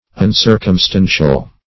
Search Result for " uncircumstandtial" : The Collaborative International Dictionary of English v.0.48: Uncircumstandtial \Un*cir`cum*stand"tial\, a. 1. Not circumstantial; not entering into minute particulars.